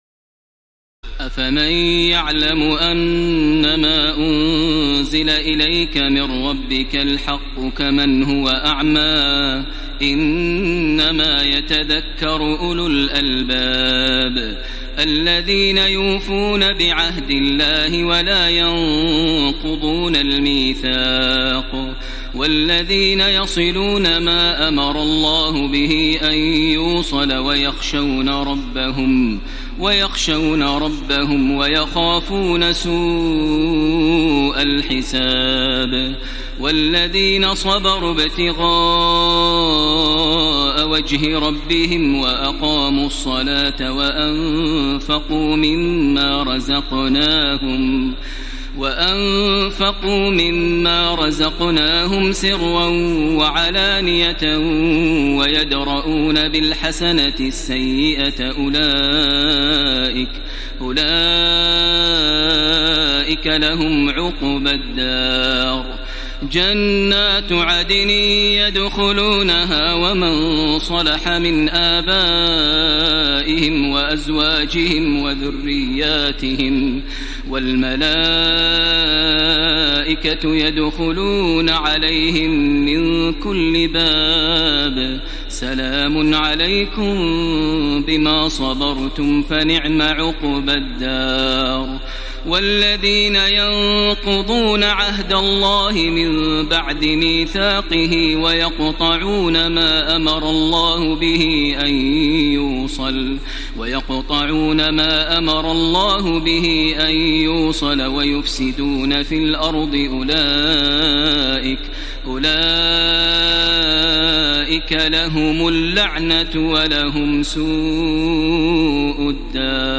تراويح الليلة الثانية عشر رمضان 1434هـ من سورتي الرعد (19-43) و إبراهيم كاملة Taraweeh 12 st night Ramadan 1434H from Surah Ar-Ra'd and Ibrahim > تراويح الحرم المكي عام 1434 🕋 > التراويح - تلاوات الحرمين